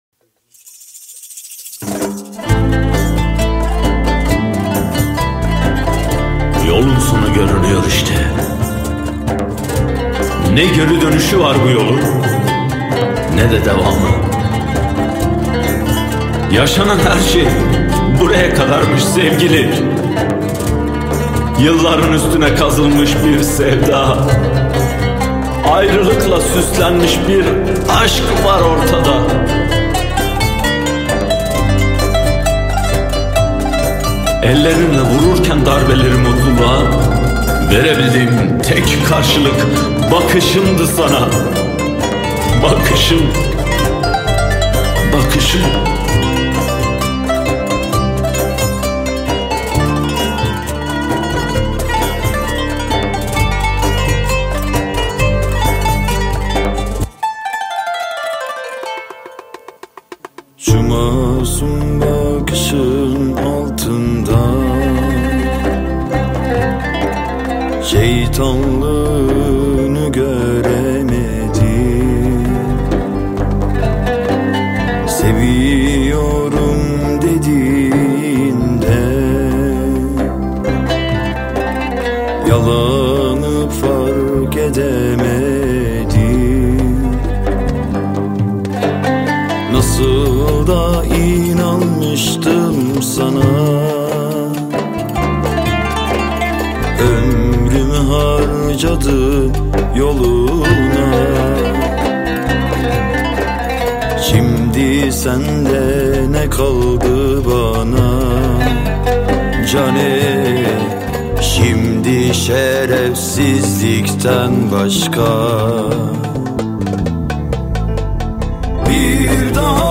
• Категория: Турецкие песни